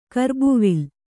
♪ karbuvil